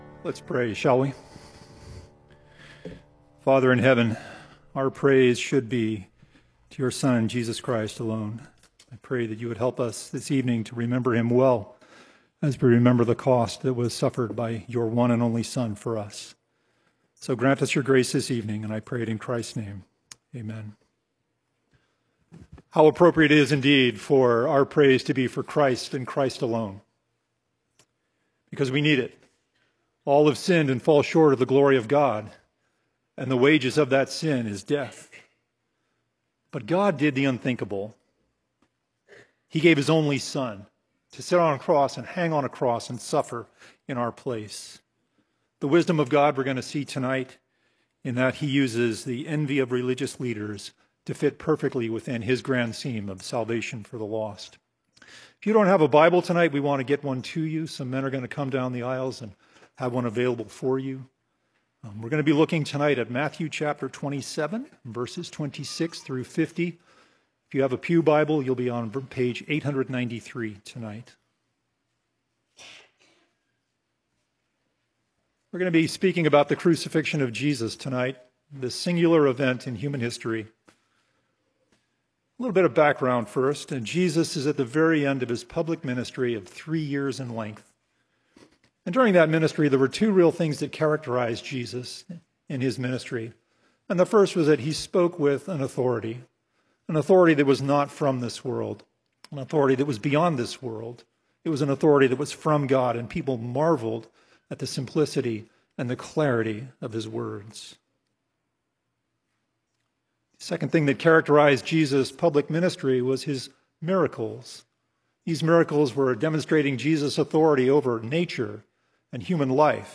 Good Friday - Matthew 27:26-50 - Grace Bible Church